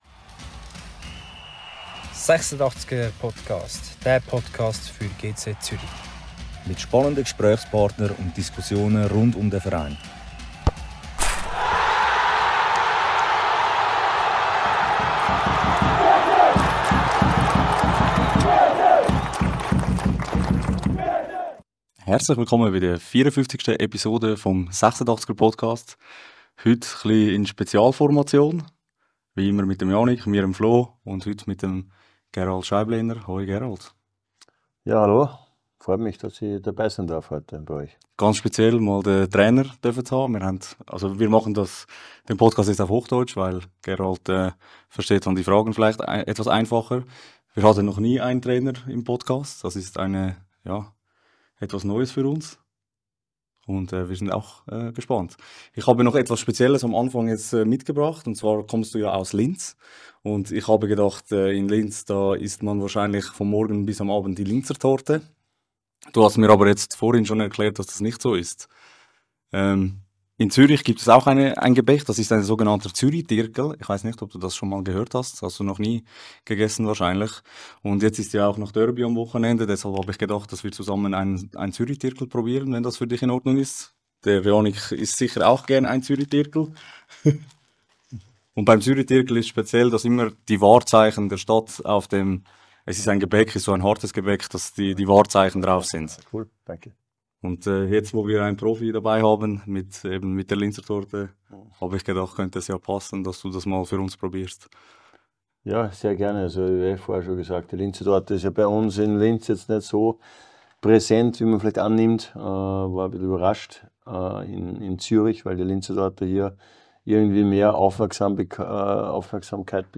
im Gespräch ~ 86'er Podcast